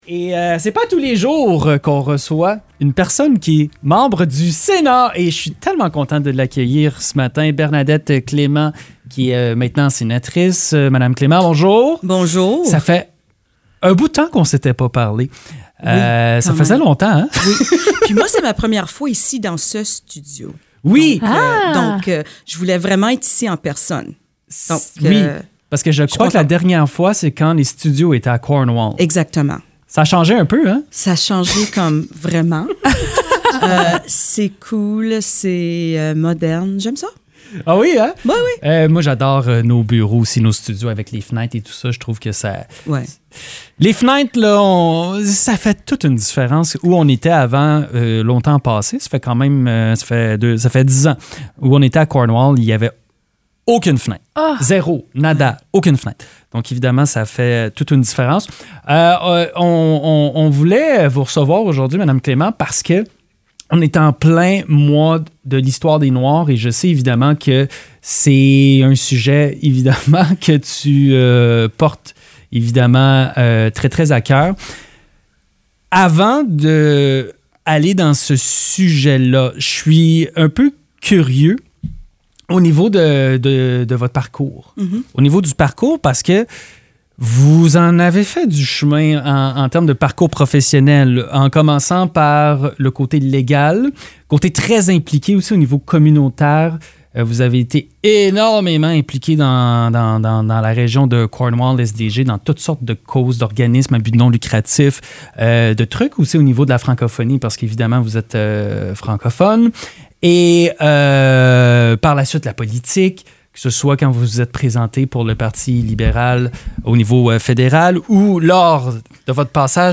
Elle est revenue sur son parcours personnel et professionnel, son arrivée dans la région de Cornwall ainsi que son engagement en politique municipale. Elle a également abordé les défis qu’elle a dû surmonter en tant que femme noire, tout en soulignant les avancées importantes réalisées au cours des dernières années en matière de représentation. Une conversation qui met en lumière le chemin parcouru et celui qu’il reste à faire.